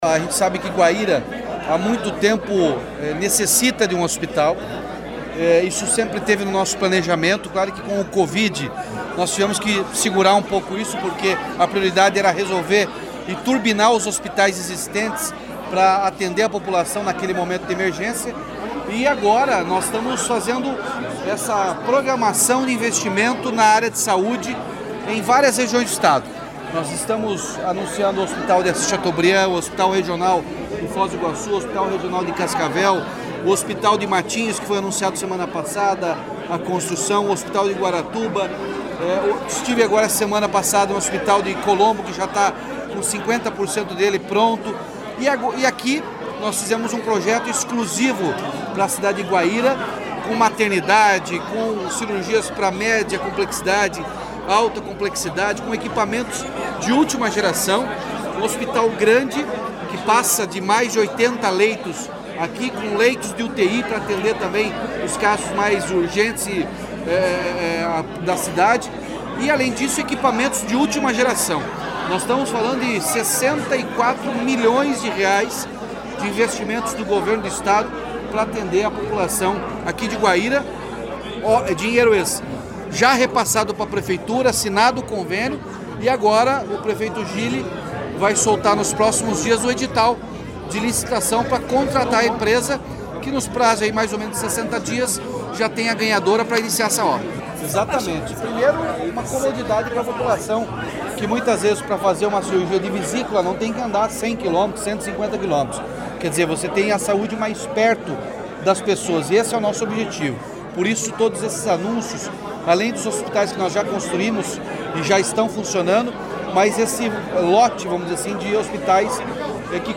Sonora do governador Ratinho Junior sobre a construção de um novo hospital em Guaíra